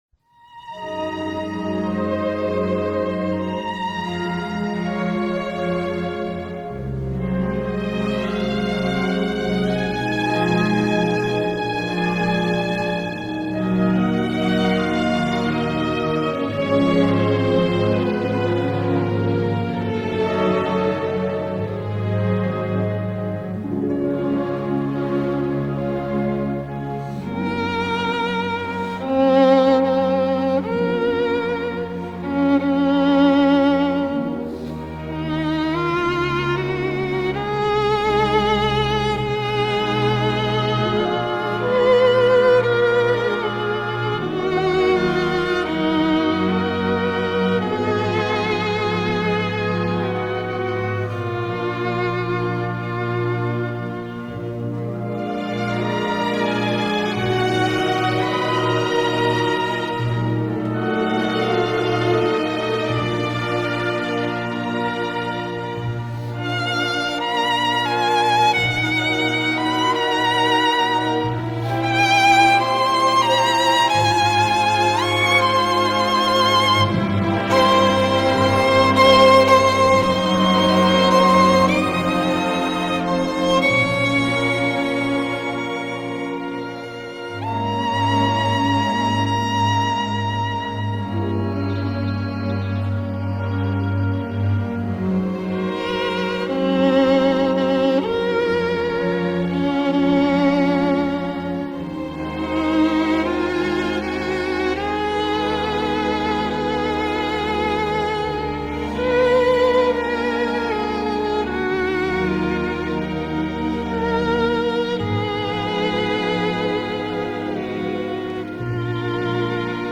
The End of The Americans (and Philip and Elizabeth Jennings) 2013-2018; Tchaikovsky’s “None But the Lonely Heart” Performed by Isaac Stern
tchaikovsky-none-but-the-lonely-heart-isaac-stern.mp3